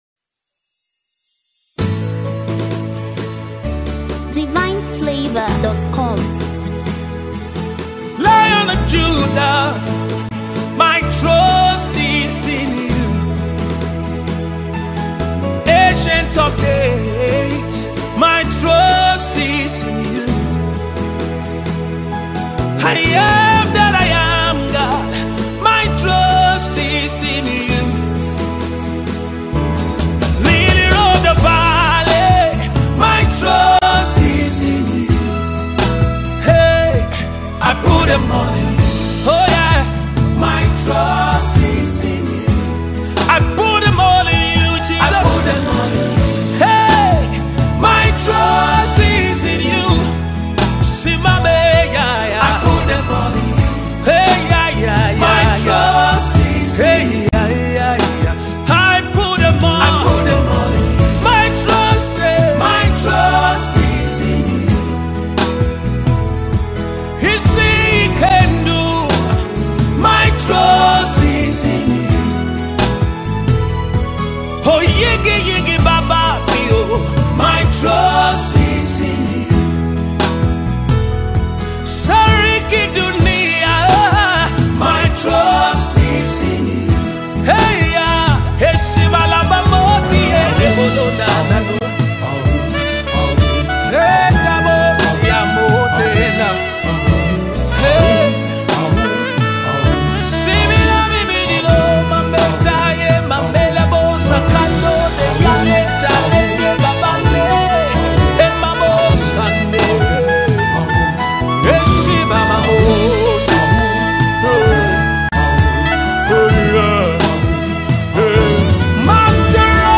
GENRE: South African Gospel.